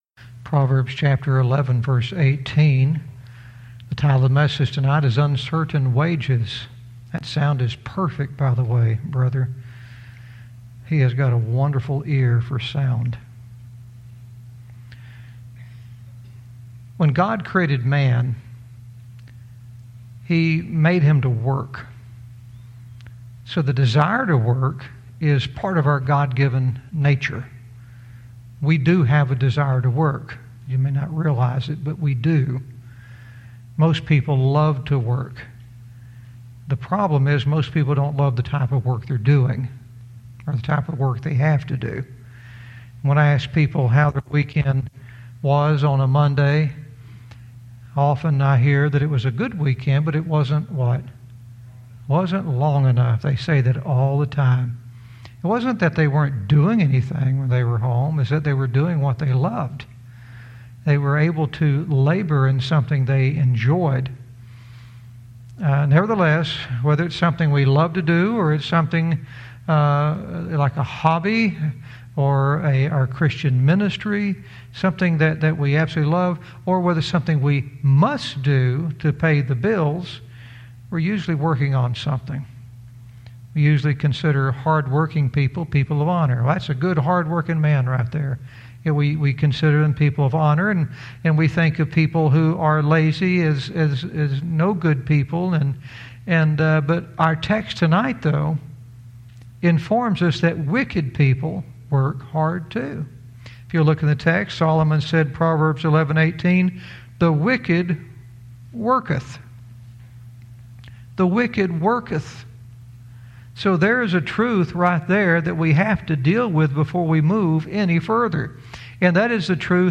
Verse by verse teaching - Proverbs 11:18 "Uncertain Wages"